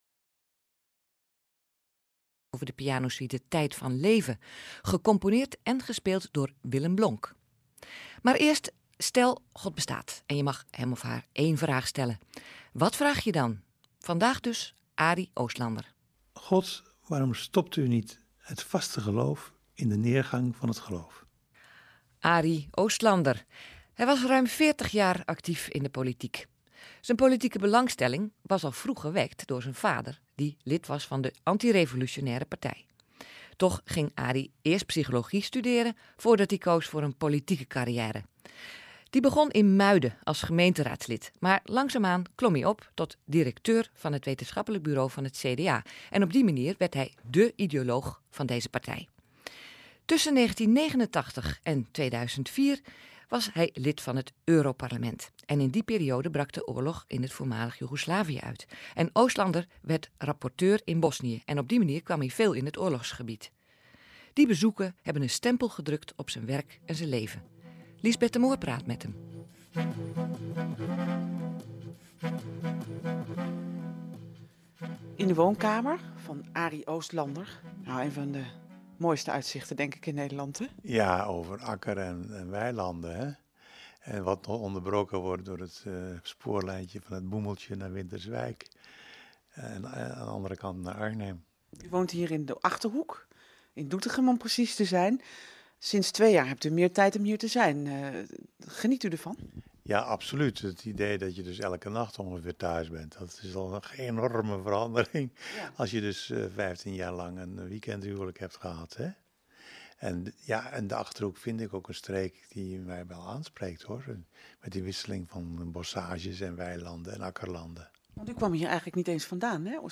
Interviews / Persberichten 2002